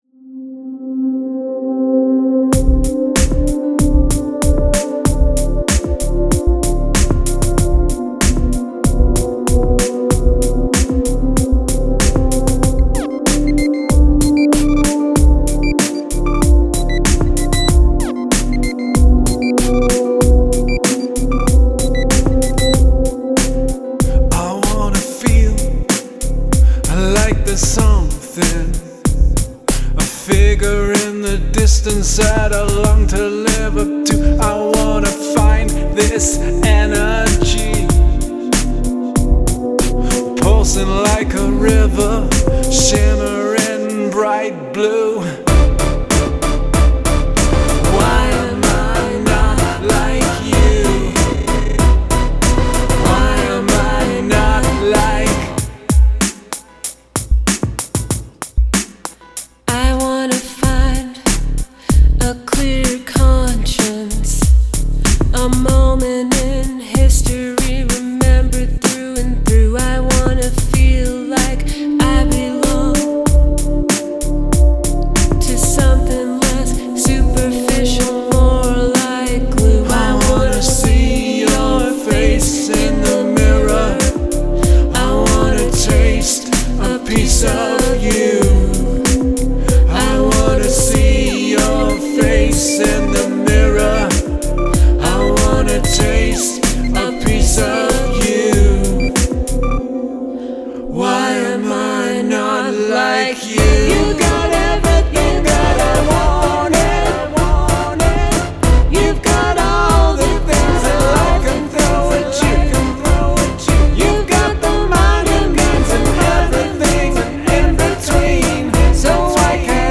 Sure, the high end is a bit harsh, but the groove was good.
Nice groove, atmospheric.